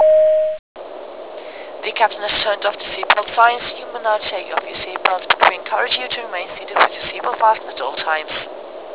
cruise-seatbelt.wav